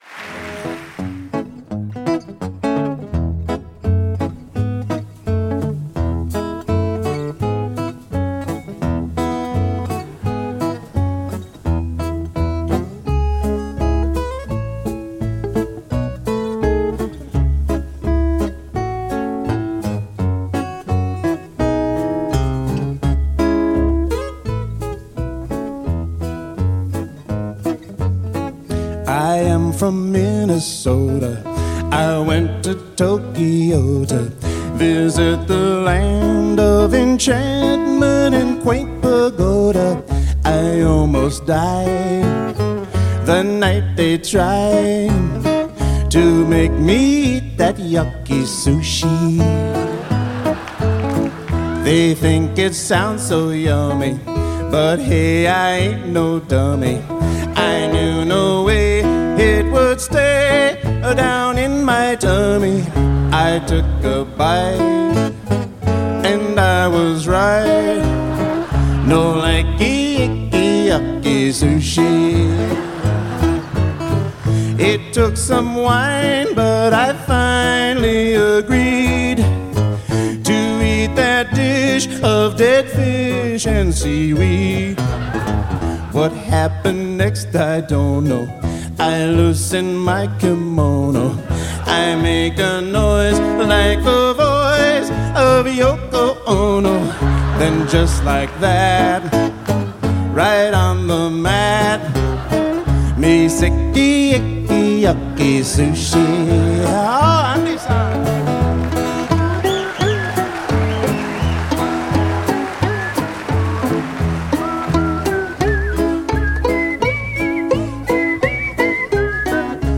one of the finest finger-picking guitarists on the planet